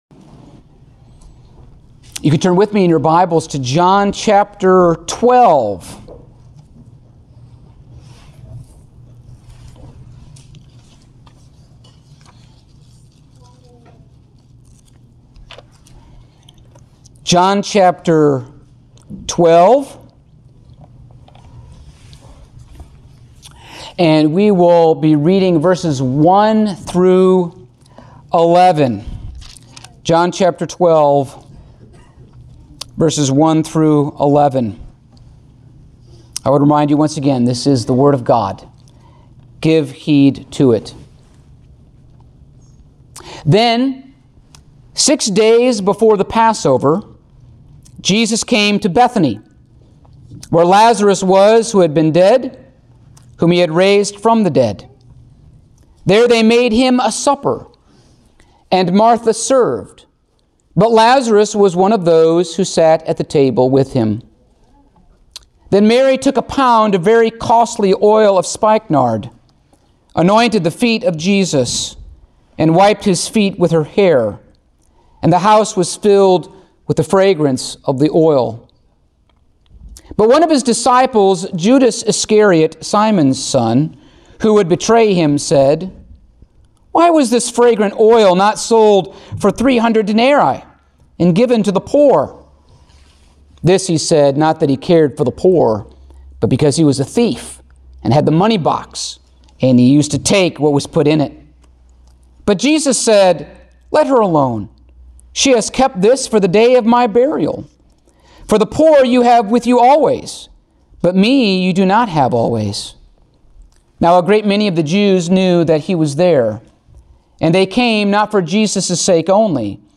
Passage: John 12:1-11 Service Type: Sunday Morning Topics